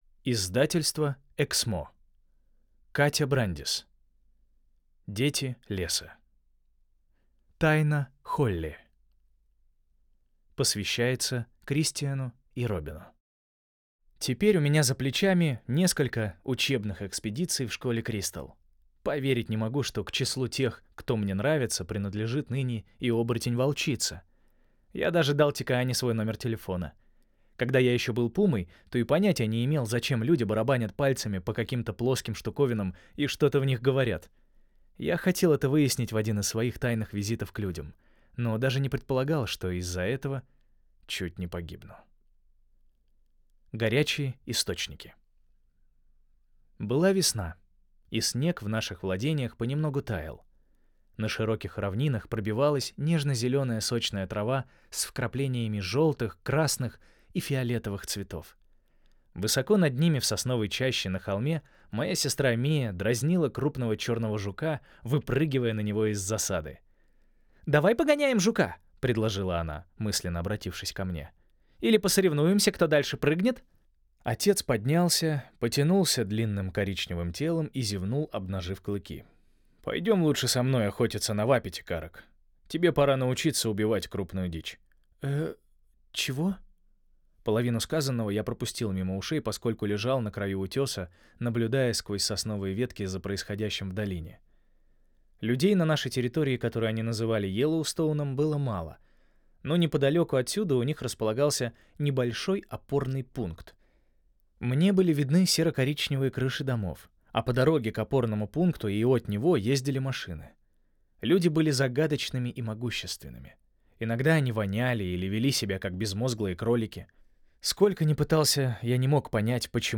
Аудиокнига Тайна Холли | Библиотека аудиокниг